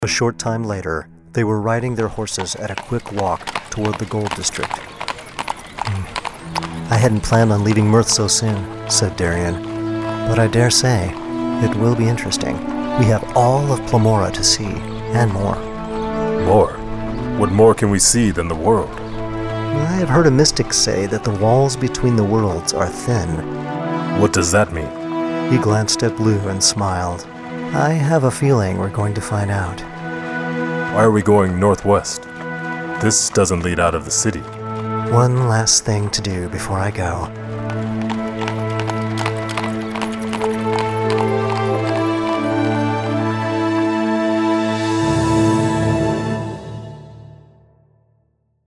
Dream Tower Media creates full audiobook dramas featuring professional voice actors, sound effects, and full symphonic musical scores.
Excerpts from One Night in Merth audiobook drama